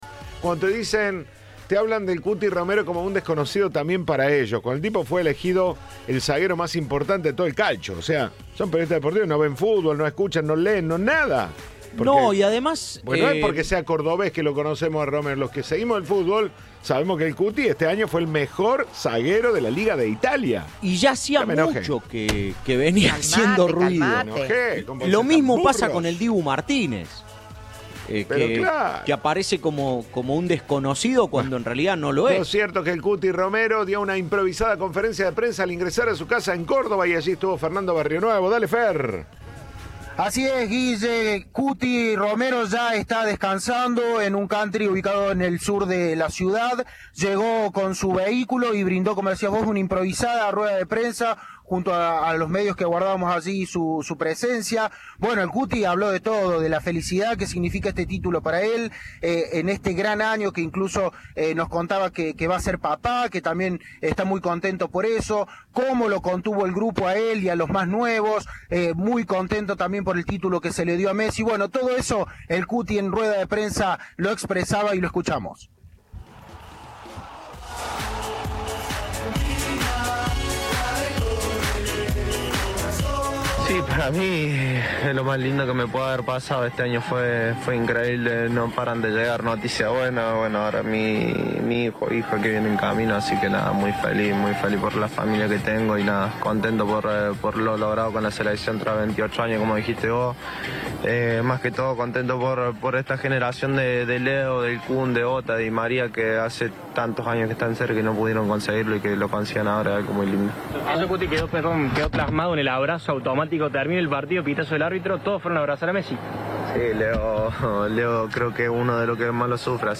El defensor brindó una conferencia de prensa improvisada al entrar a su casa y expresó su alegría por haber logrado el título con la generación de Messi, Agüero, Di María y Otamendi.